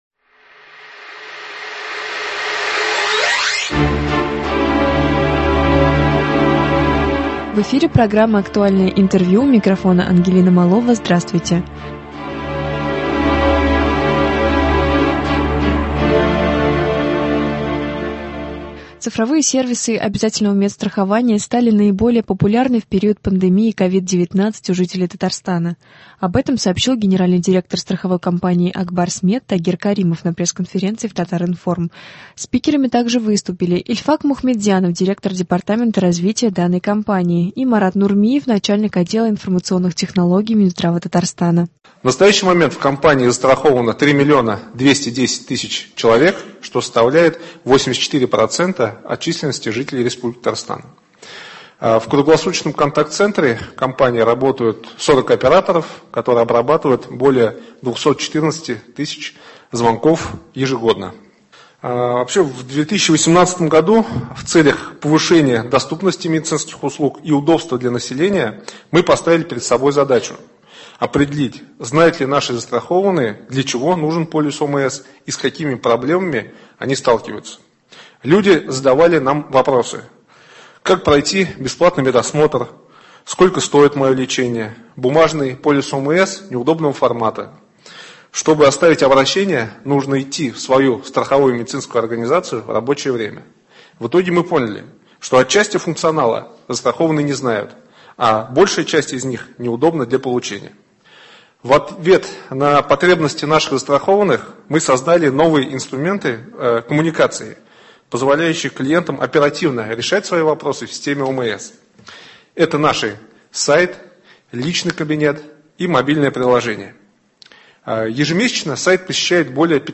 Актуальное интервью(10.02.21)